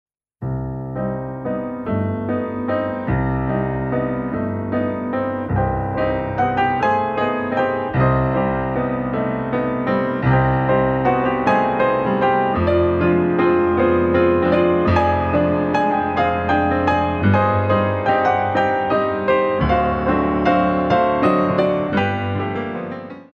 Valse pointes